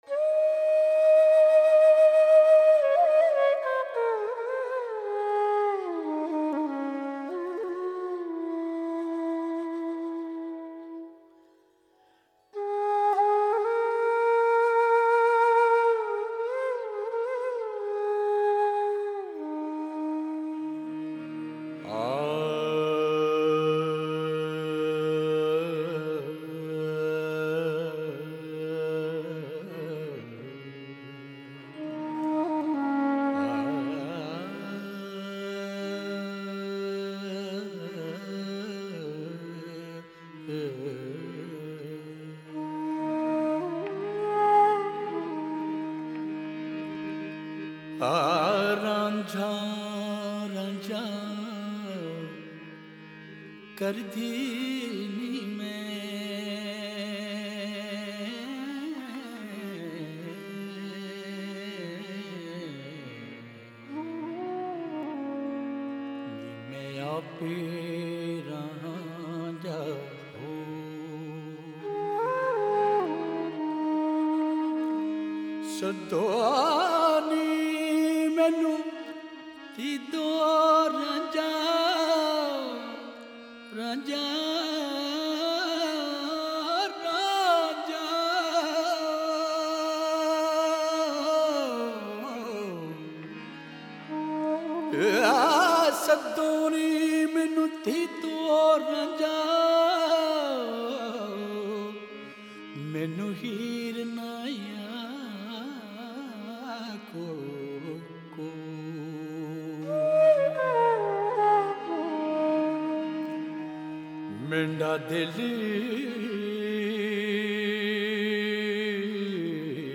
Punjabi Kalam